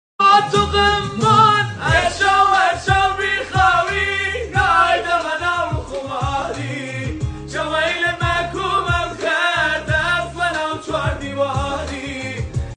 اجرای زنده
بصورت دلی منتشر شد